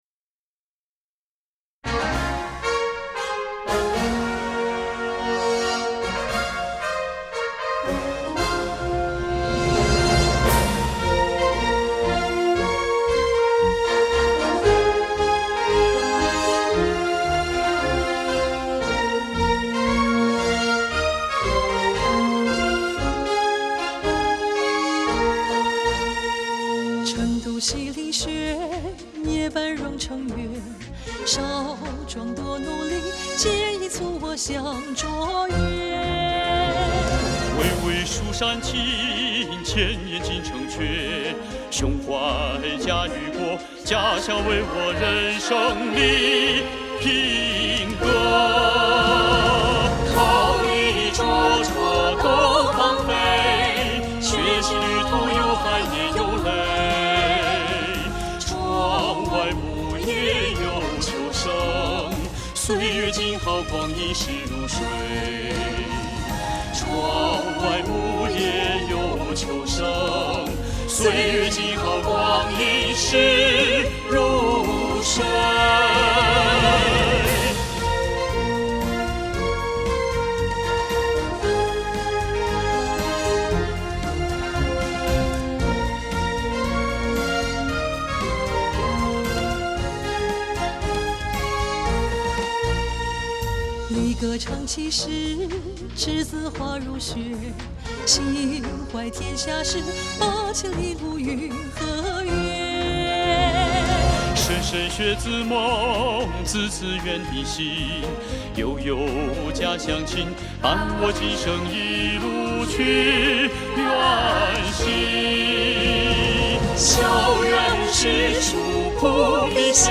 师生演绎十首
优美原创歌曲